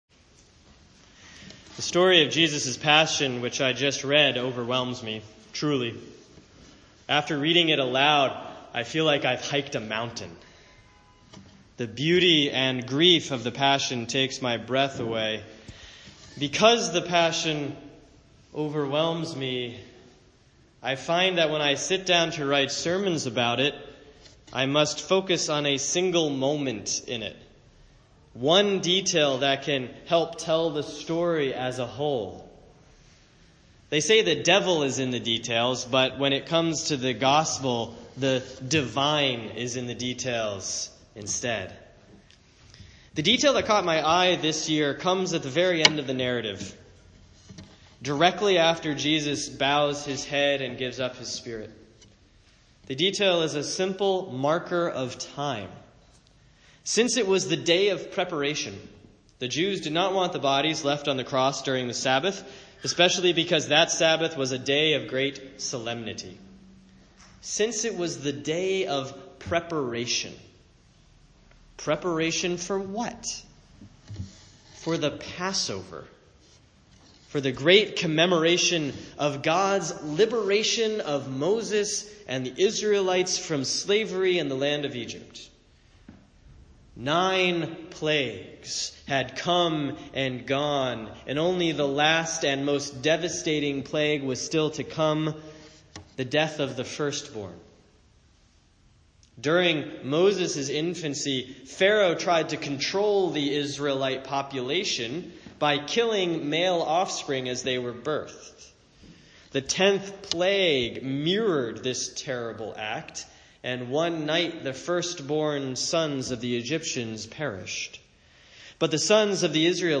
Sermon for Good Friday, April 14, 2017 || The Passion according to John